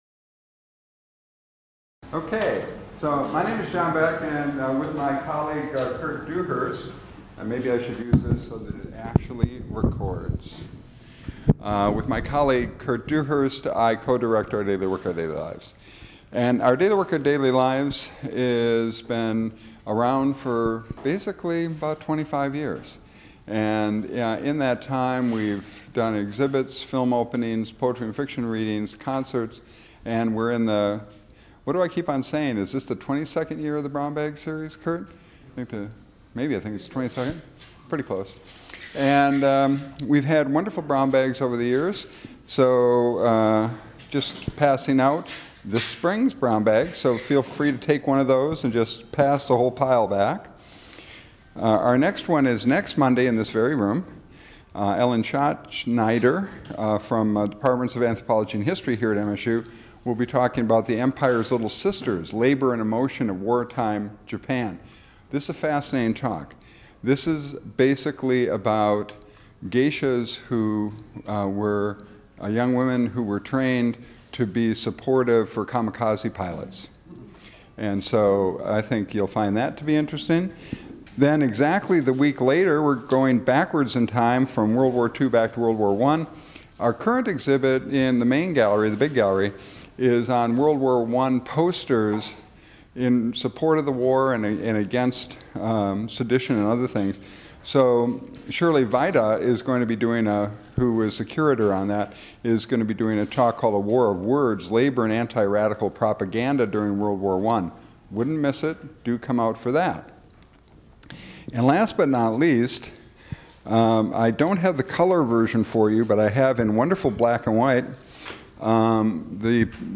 She answers questions from the audience.